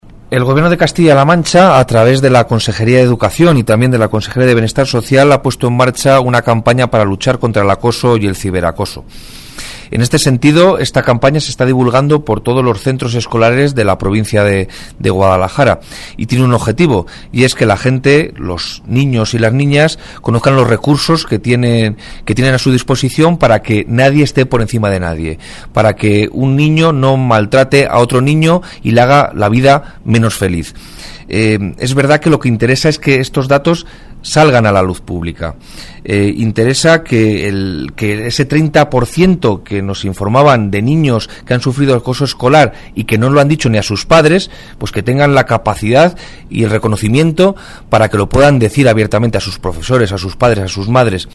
El delegado de la Junta en Guadalajara, Alberto Rojo, habla de la campaña contra el acoso puesta en marcha por el Gobierno regional